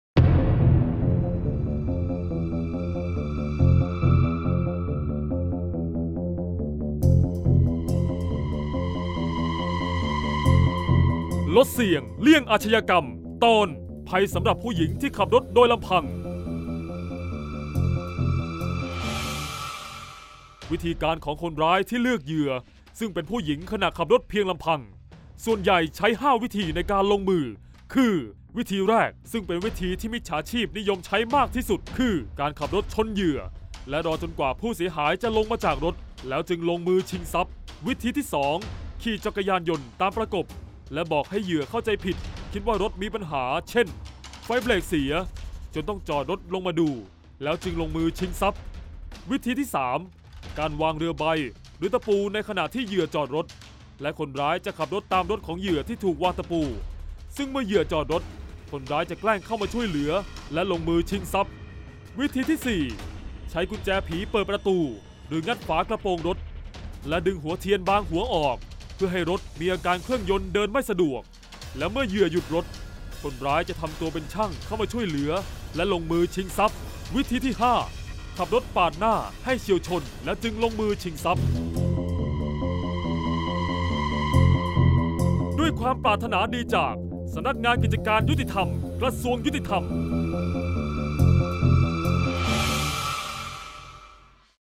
ชื่อเรื่อง : เสียงบรรยาย ลดเสี่ยงเลี่ยงอาชญากรรม 47-ภัยผู้หญิงขับรถลำพัง